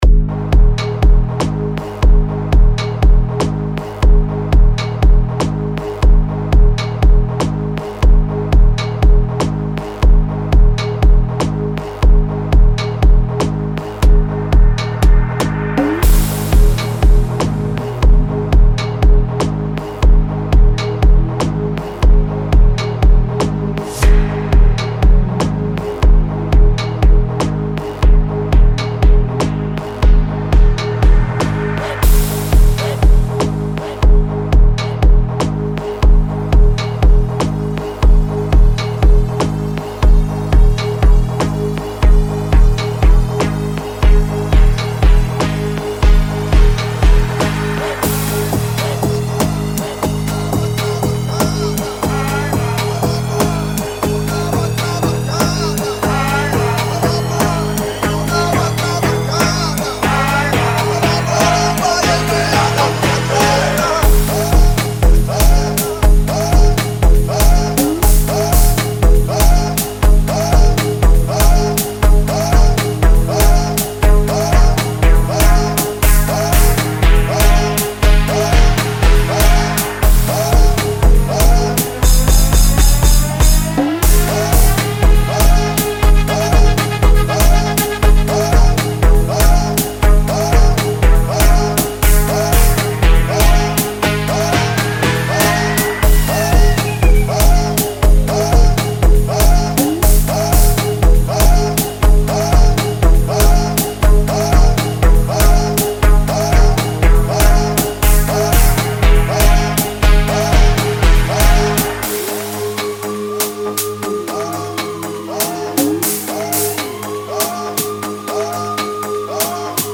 2024 Categoria: Amapiano Download RECOMENDAÇÕES